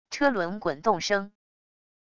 车轮滚动声wav音频